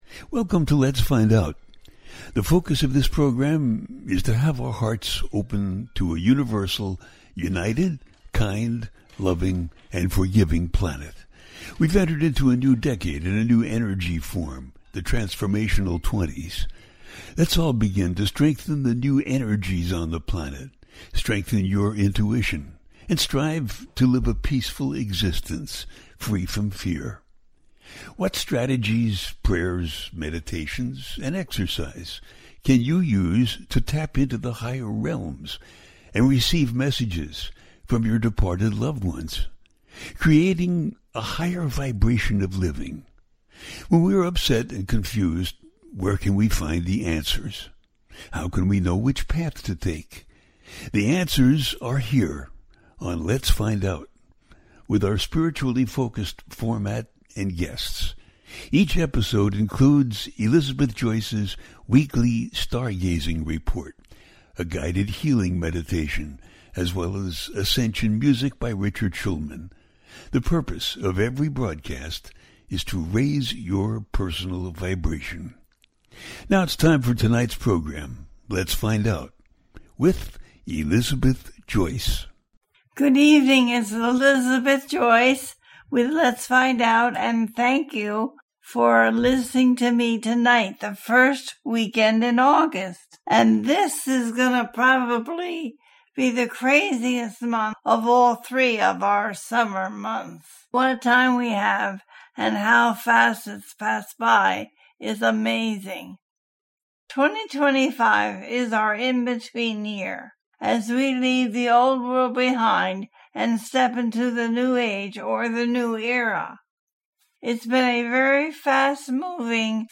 Full Moon In Aquarius And Movement Of The Outer Planets - A teaching show
The listener can call in to ask a question on the air.
Each show ends with a guided meditation.